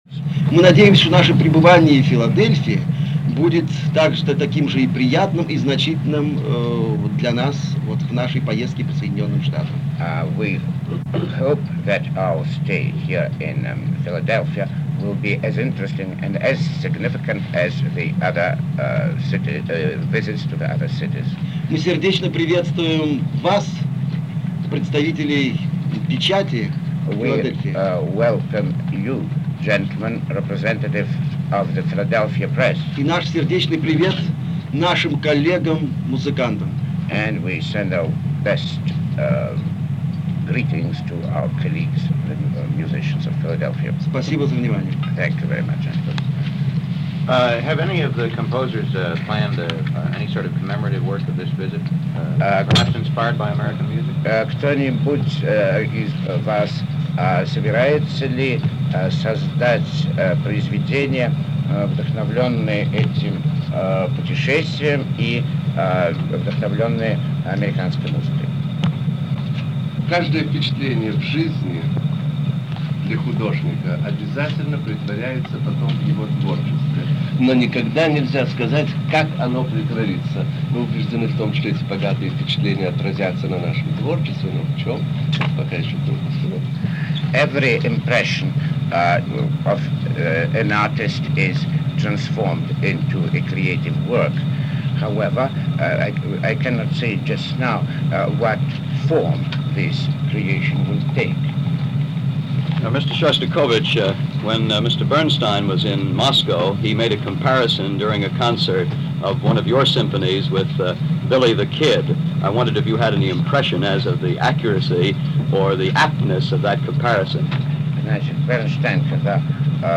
A Shostakovich Press Conference - 1959 - Past Daily Weekend Gallimaufry - recorded November 6, 1959 - NBC Radio.